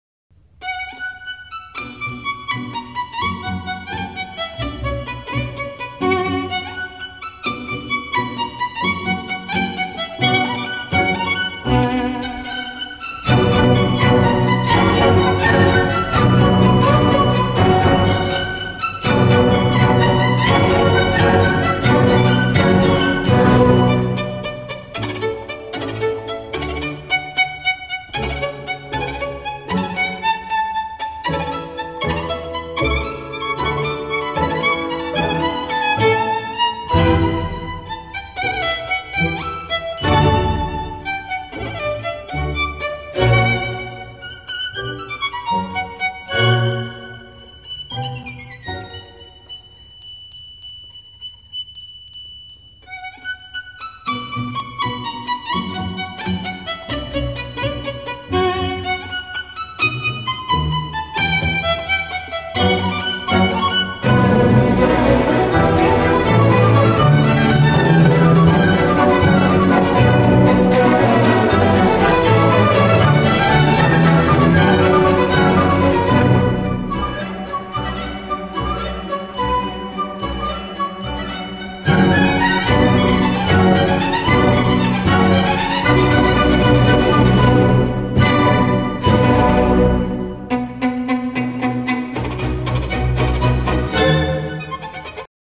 скрипка